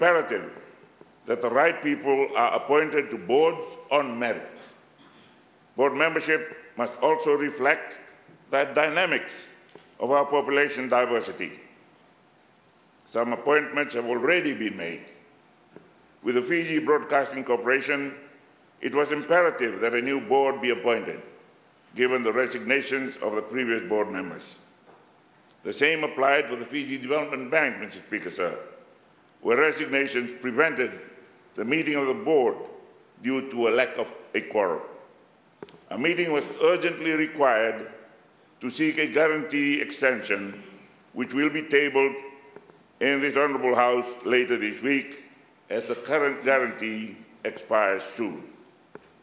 Prime Minister Sitiveni Rabuka highlighted this in Parliament this morning.